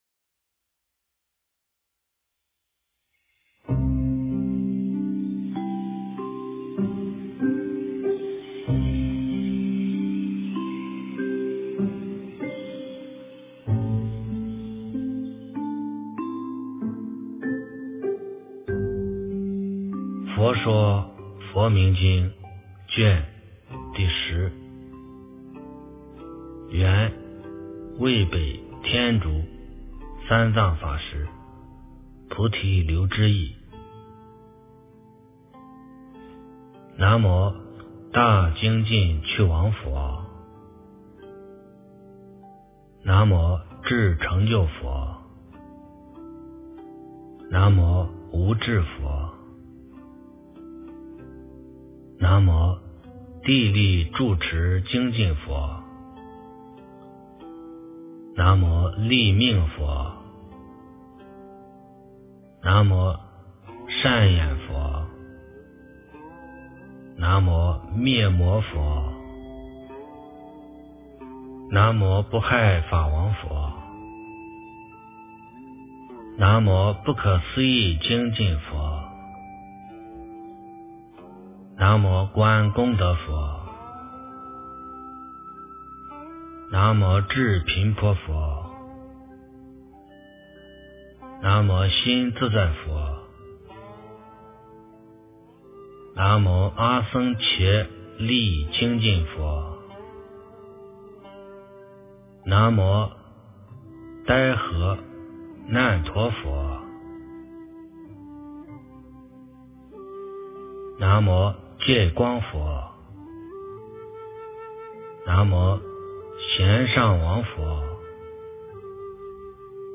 万佛名经第10卷 - 诵经 - 云佛论坛